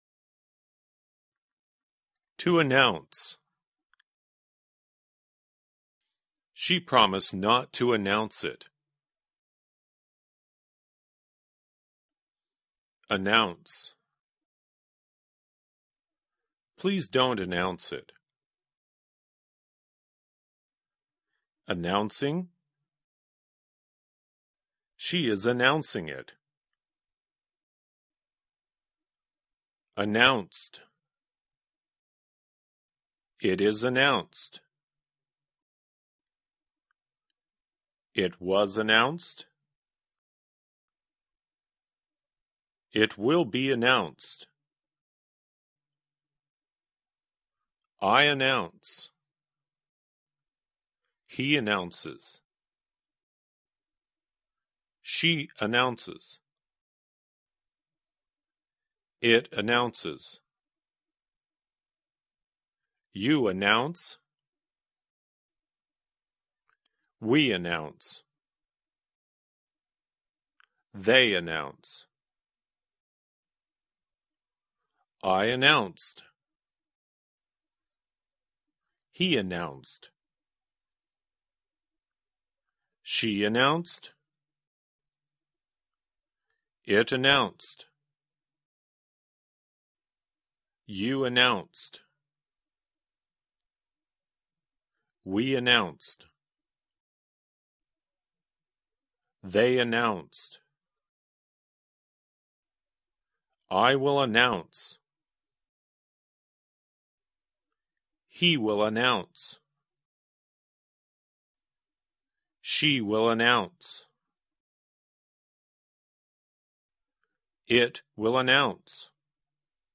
在线英语听力室免费英语课程培训-American Lesson 8e的听力文件下载,免费英语课程培训,纯外教口语,初级学习-在线英语听力室